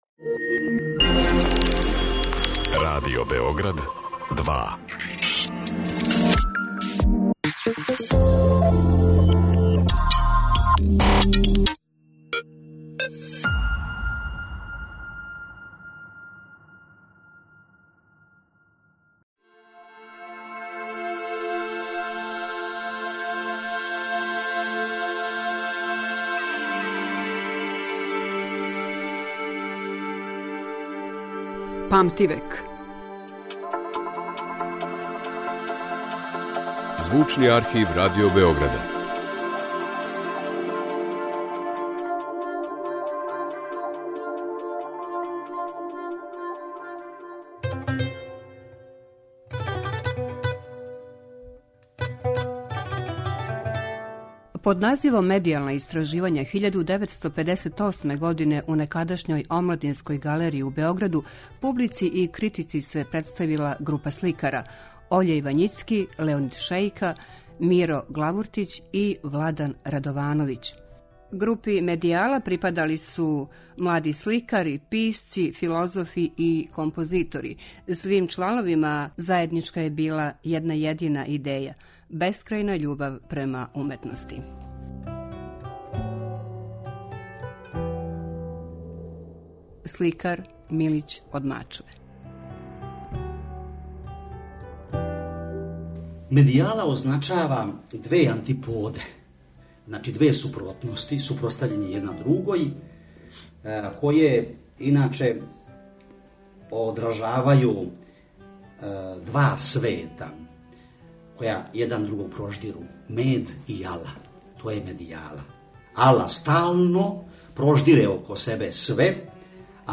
Избор из Тонског архива Радио Београда